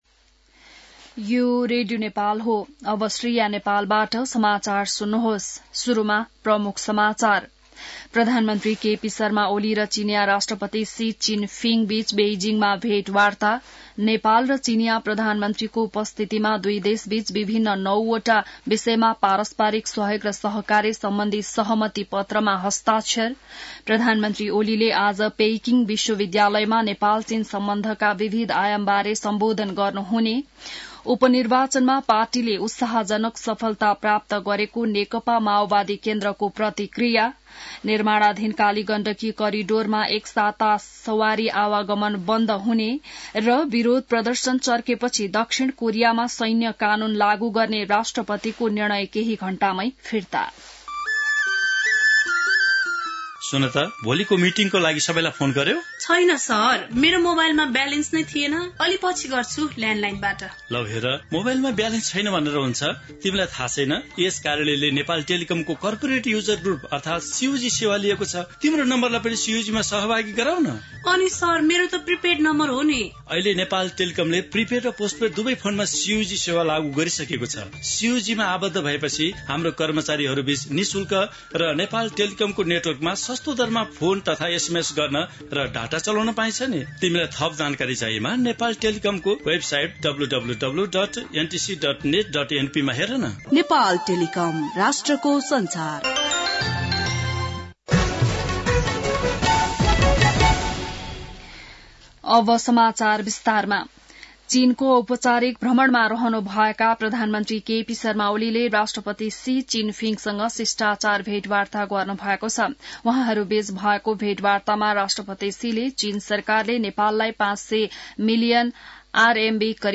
बिहान ७ बजेको नेपाली समाचार : २३ मंसिर , २०८१